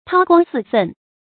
韜光俟奮 注音： ㄊㄠ ㄍㄨㄤ ㄙㄧˋ ㄈㄣˋ 讀音讀法： 意思解釋： 謂掩藏才智，待時奮起。